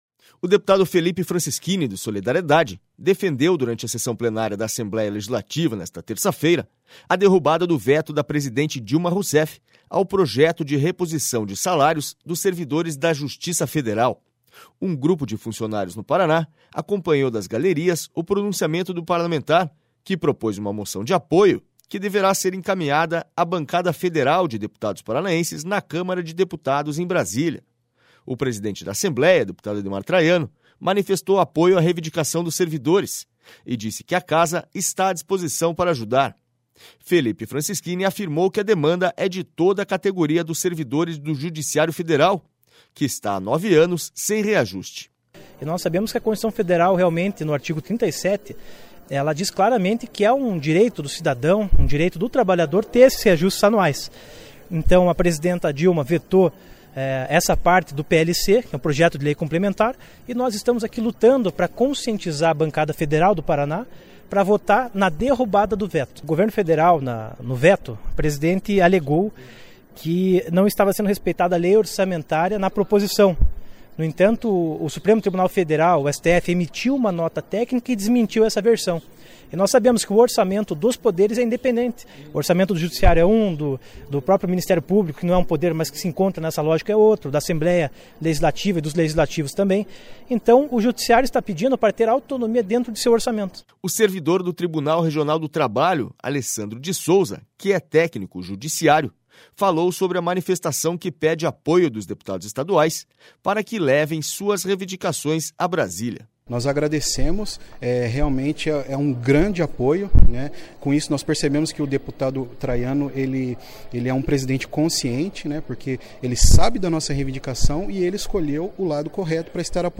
O deputado Felipe Francischini, do SD, defendeu durante a sessão plenária da Assembleia Legislativa nesta terça-feira a derrubada do veto da presidente Dilma Rousseff ao projeto de reposição de salários dos servidores da Justiça Federal.//Um grupo de funcionários no Paraná acompanhou das galerias o...